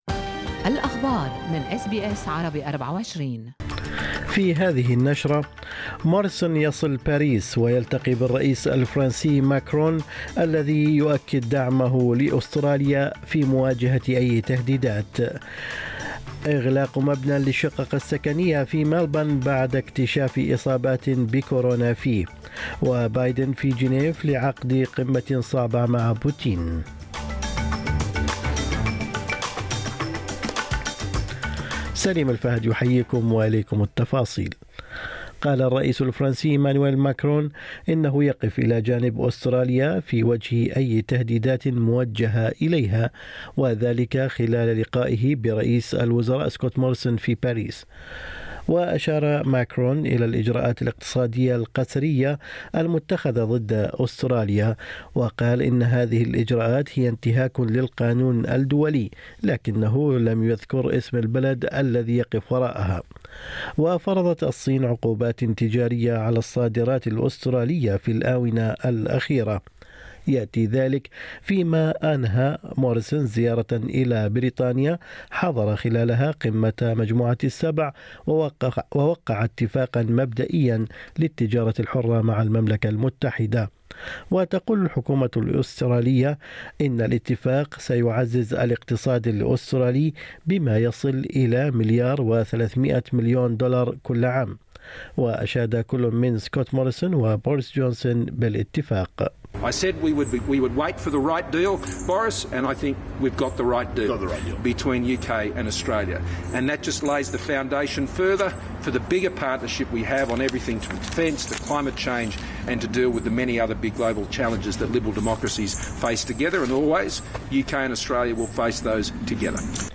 نشرة أخبار الصباح 16/6/2021